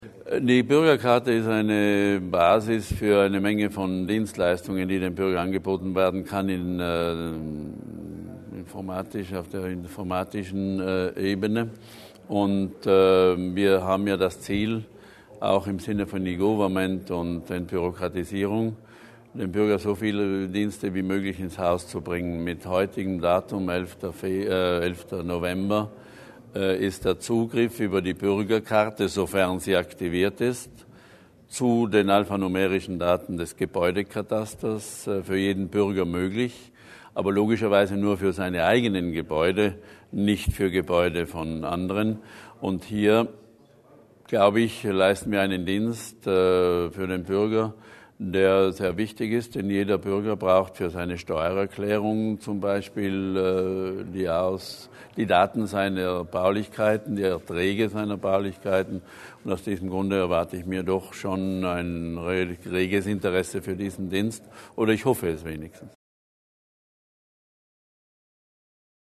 Landesrat Berger über die neuen Dienste des Kataster